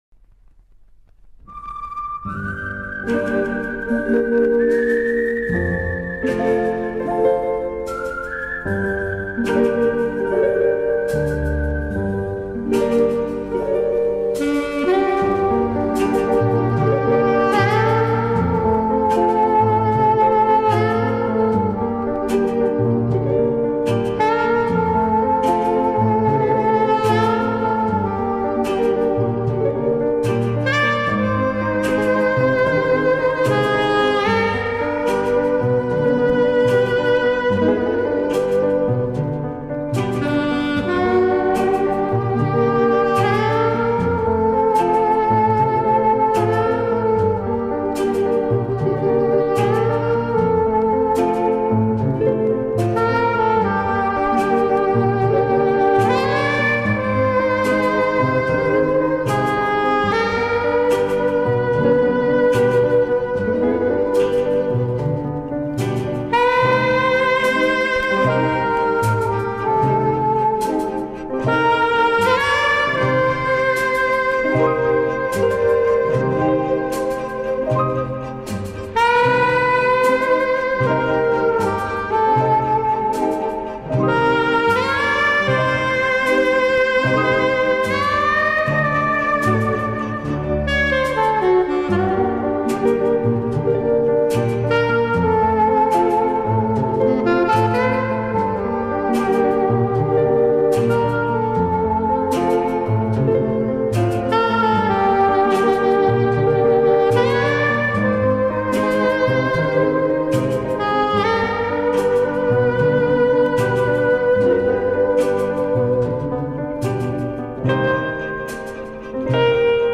опознать спокойную, лирическую мелодию на саксофоне